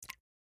drip_water3.ogg